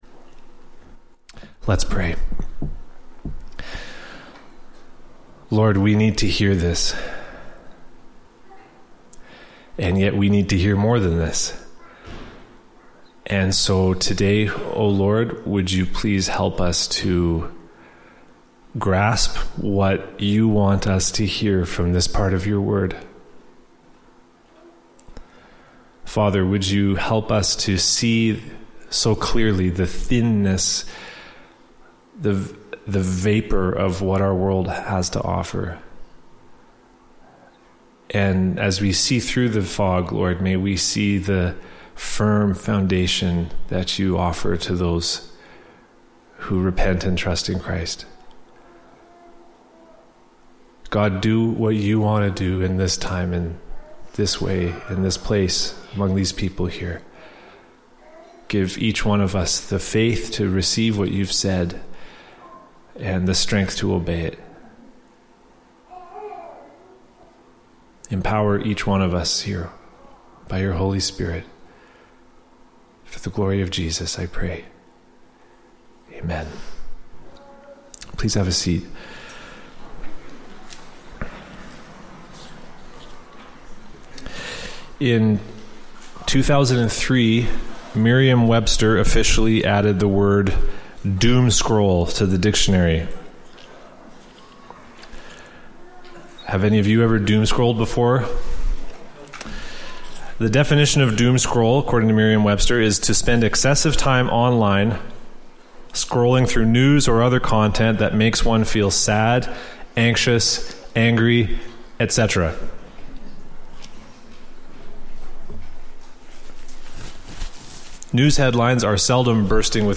Service Type: Latest Sermon